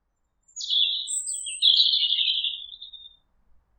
birds02.wav